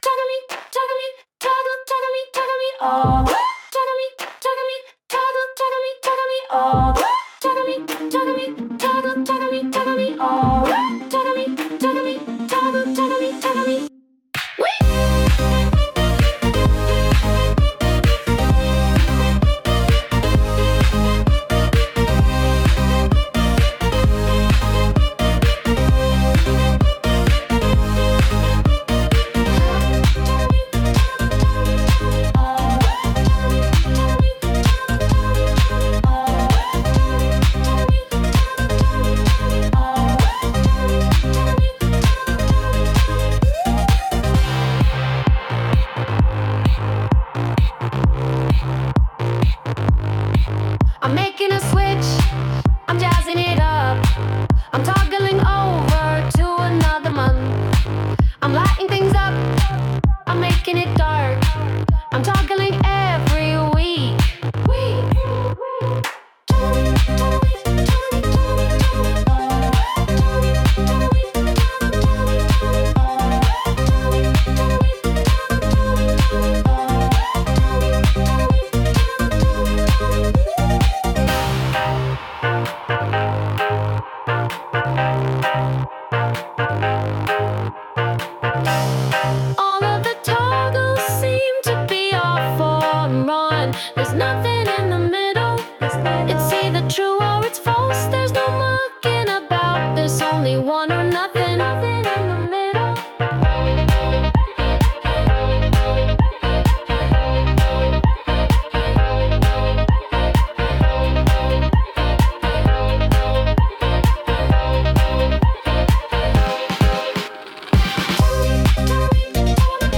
Sung by Suno
Toggle_Me,_Toggle_Me,_Toggle_(Remix)_mp3.mp3